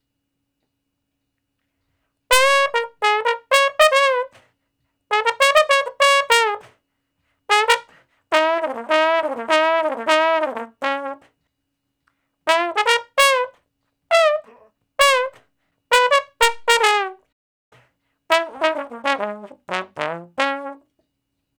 099 Bone Straight (Db) 03.wav